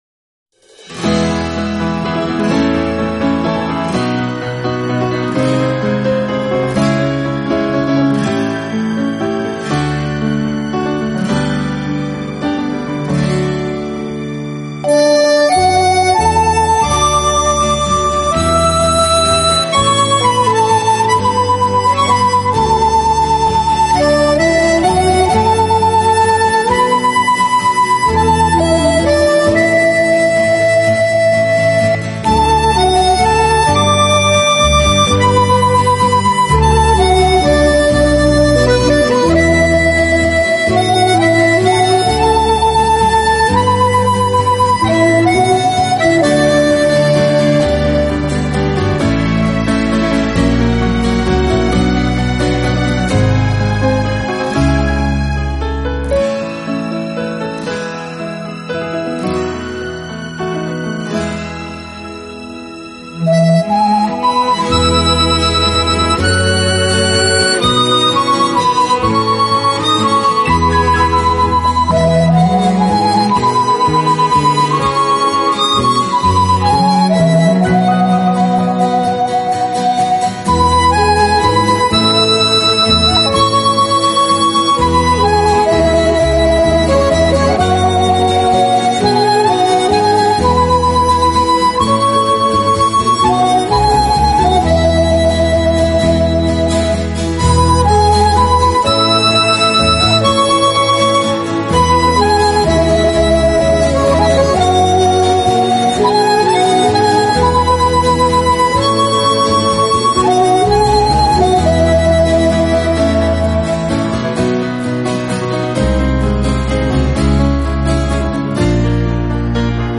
Genre: Instrumental